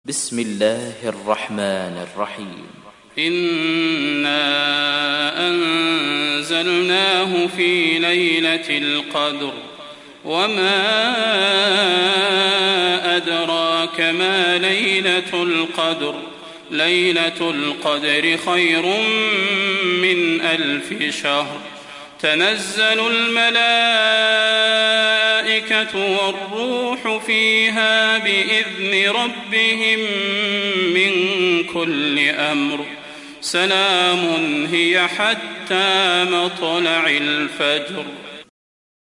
تحميل سورة القدر mp3 بصوت صلاح البدير برواية حفص عن عاصم, تحميل استماع القرآن الكريم على الجوال mp3 كاملا بروابط مباشرة وسريعة